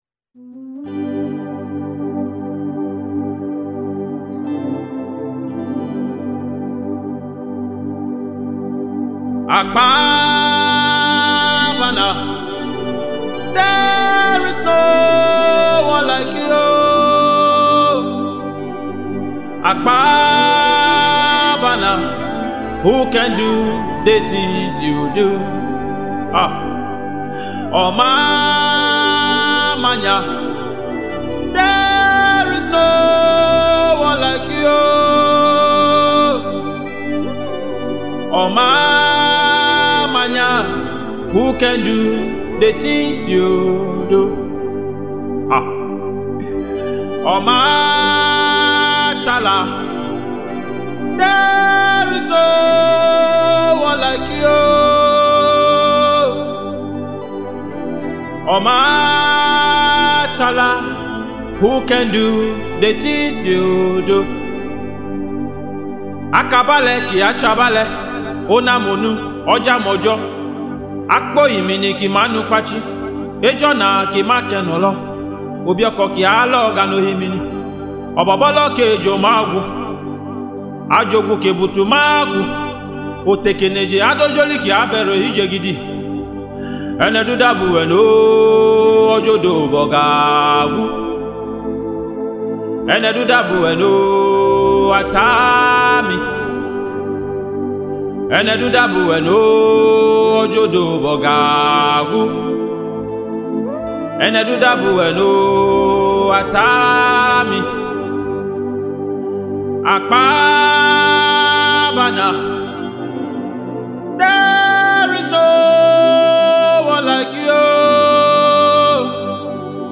Igala worship song